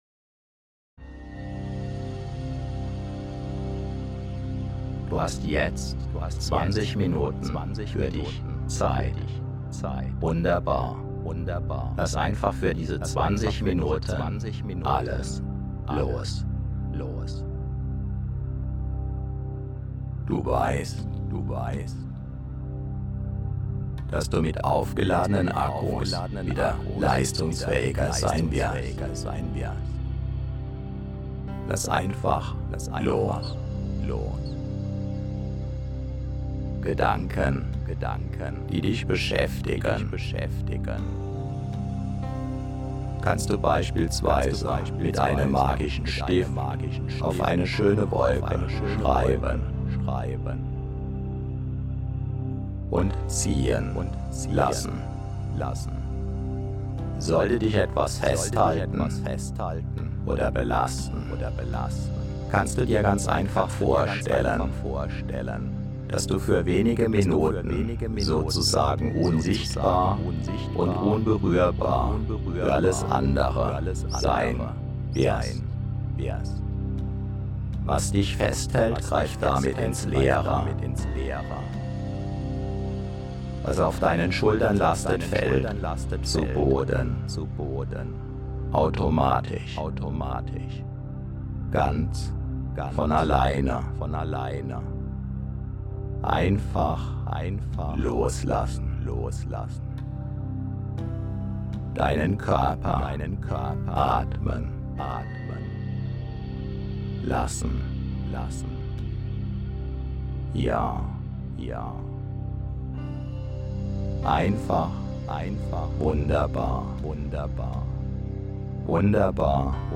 Weil JEDES Audio bereits eine eingebaute Weckfunktion hat brauchst du dir niemals wieder den Wecker nach einem Power-Nap zu stellen.
10-Minuten-Hörproben der 20-Minuten-Powernap in allen 12 Varianten
Variante 20 min, asynchrone Doppelinduktion, Hintergrundmusik #3